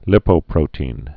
(lĭpō-prōtēn, -tē-ĭn, līpō-)